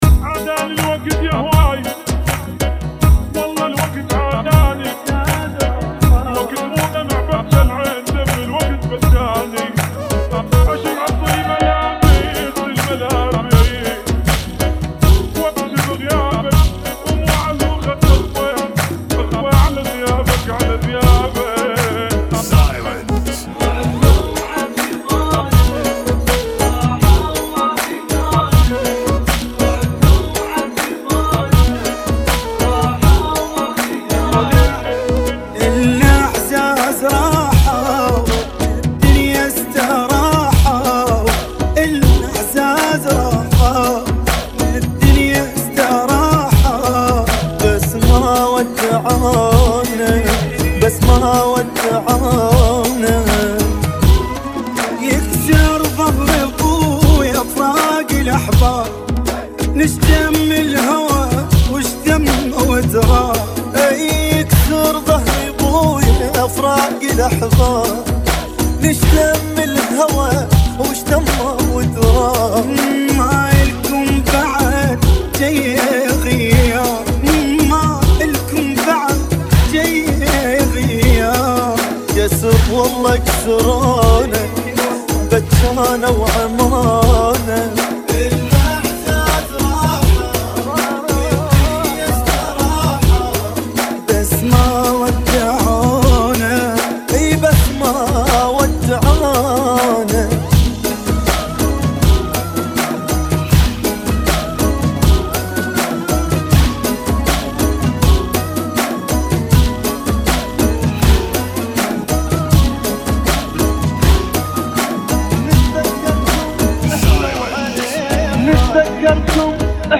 [ 80 Bpm ]